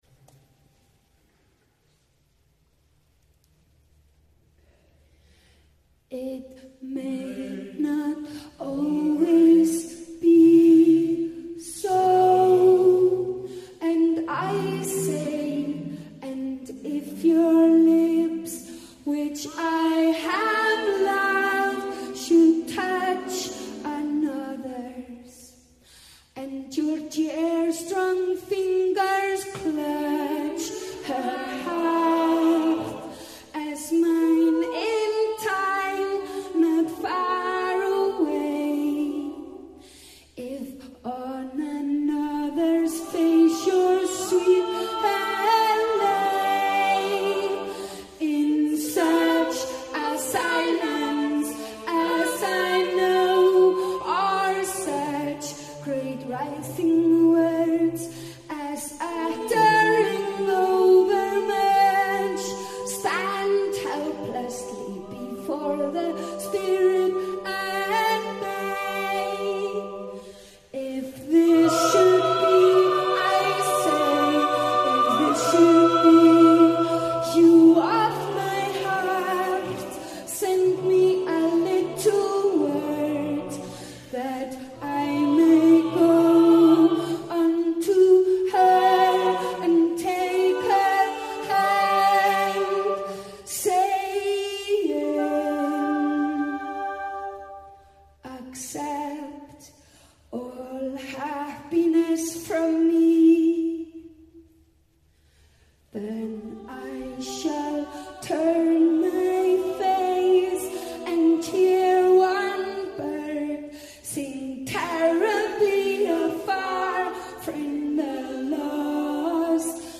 Langholtskirkja Church 26/08/08 Reykjavík